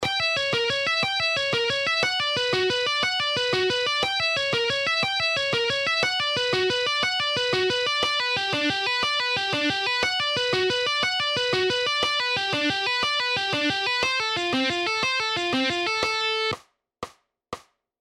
Guitar Sweeping Exercises – 3
Sweep-Picking-Exercise-3-1.mp3